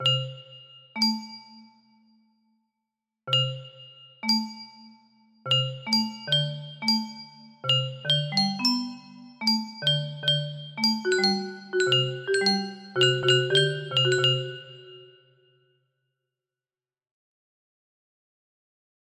layer 1 music box melody